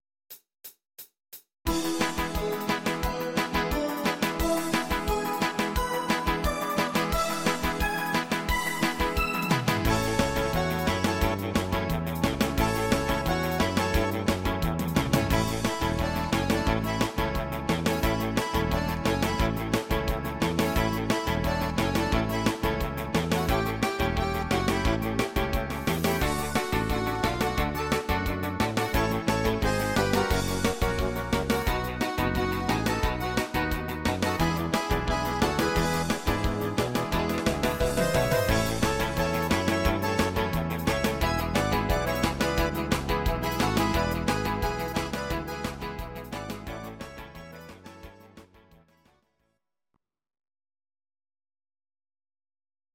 Audio Recordings based on Midi-files
German, 2000s